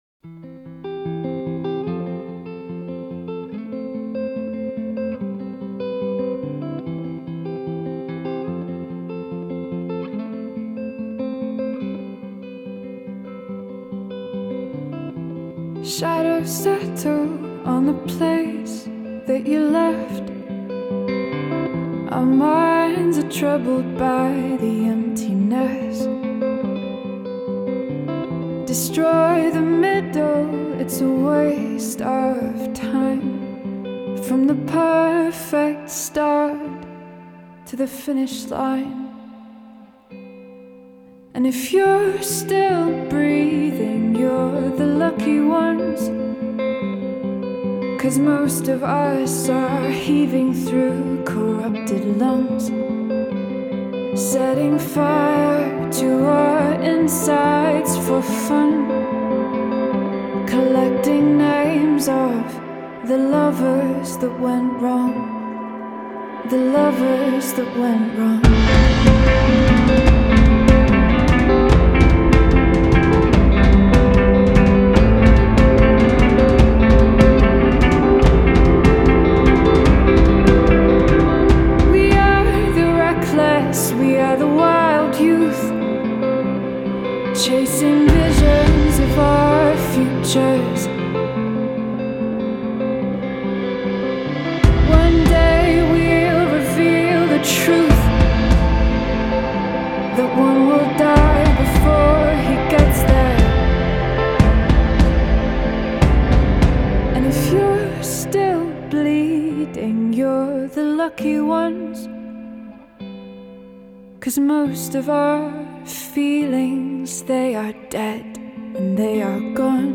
Жанр: Indie / Folk Rock